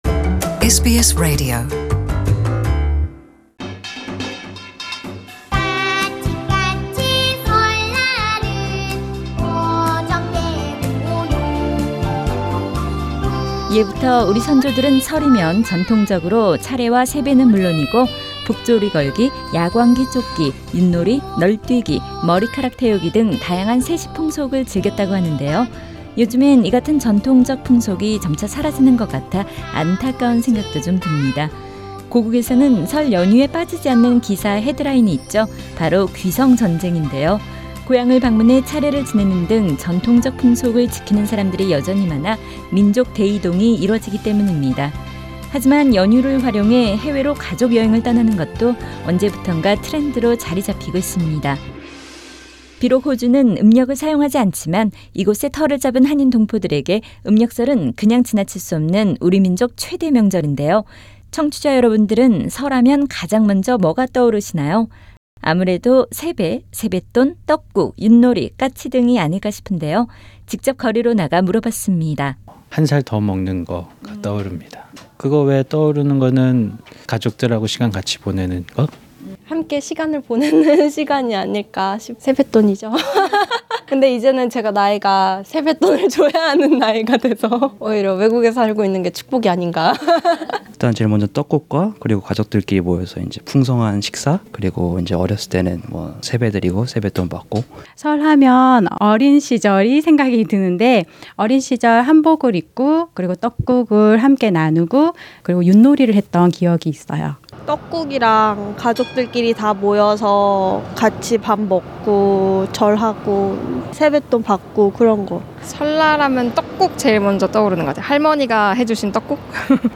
오늘 제1부에서는 한인 동포들과 음력설을 쇠는 아시아 국가 출신들이 호주에서 설을 어떻게 보내는지 직접 들어본다.